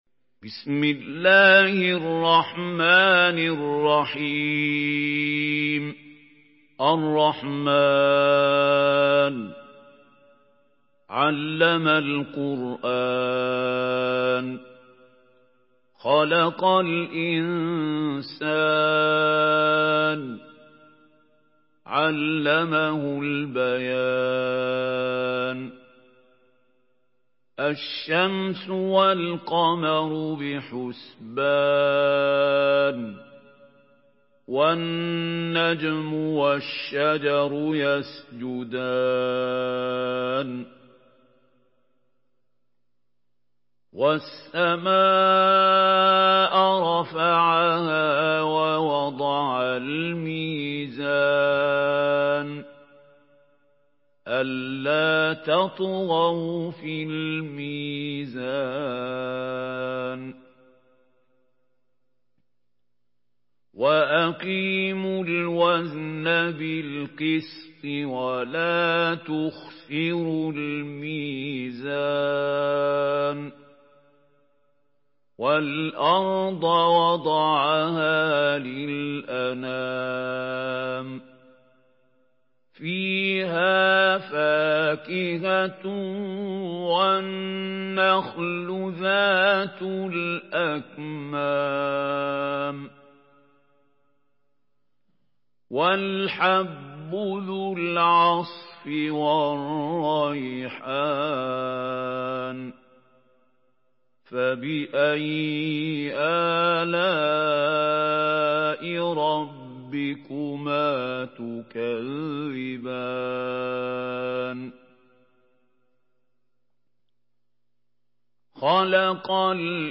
سورة الرحمن MP3 بصوت محمود خليل الحصري برواية حفص
مرتل حفص عن عاصم